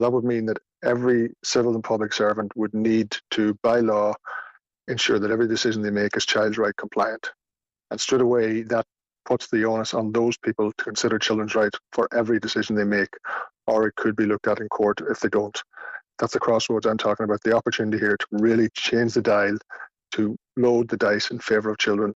Dr Niall Muldoon says it’s time children’s rights were fully incorporated into Irish law: